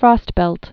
(frôstbĕlt, frŏst-)